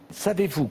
Sarkozy’s [s] and [z] are generally less hi-f, but for example his emphatic savez-vous at 0:17 begins with an [s] which is decidedly hi-f by cross-linguistic standards: